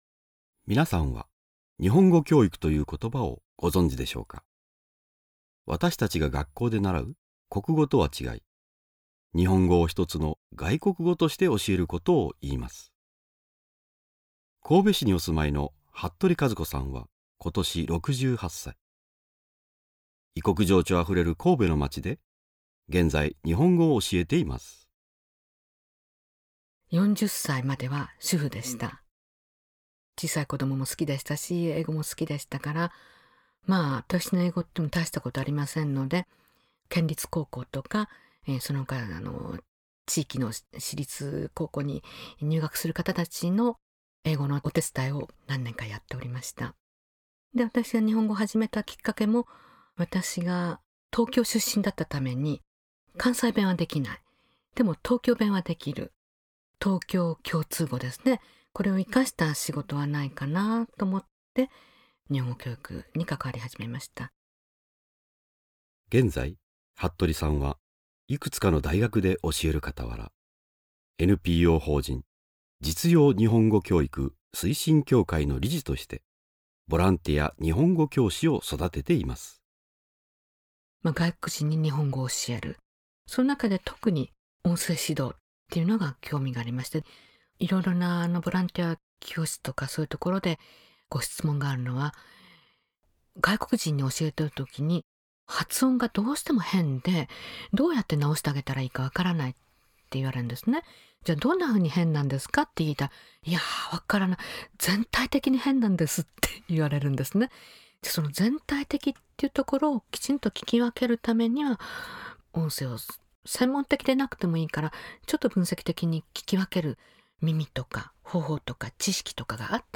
信者さんのおはなし